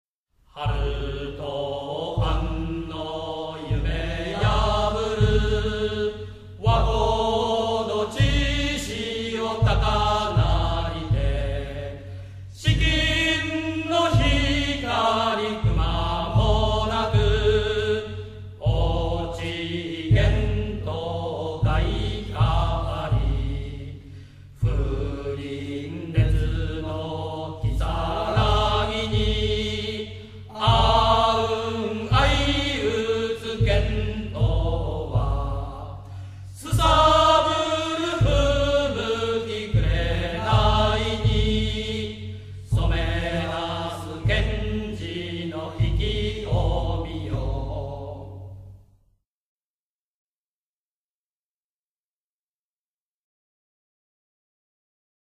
羽咋高校応援歌